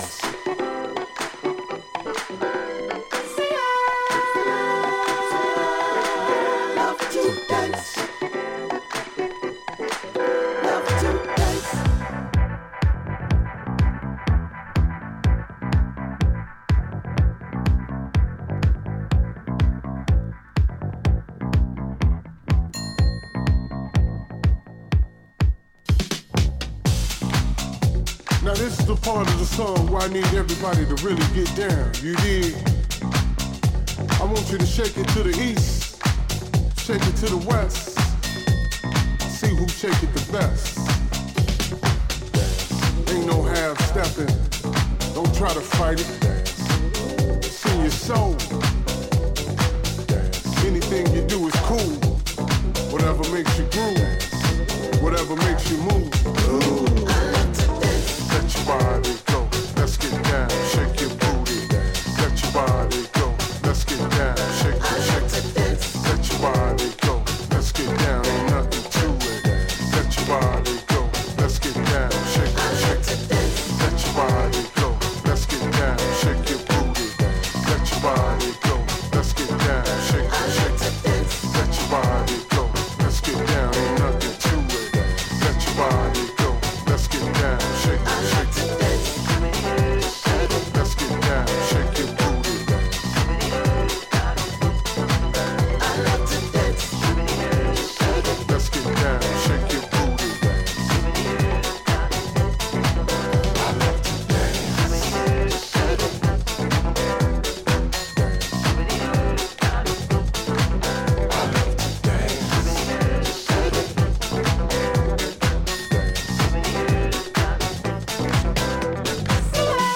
a disco-house peak-time weapon